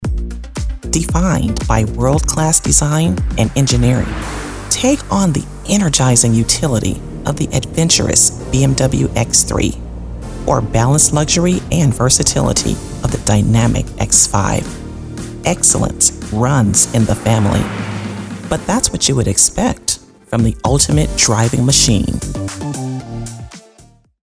Female
My voice is authentic, smooth and can be raspy at times. It is professional, articulate, trustworthy, assertive, warm, inviting and believable. It's also quite soothing.
Radio Commercials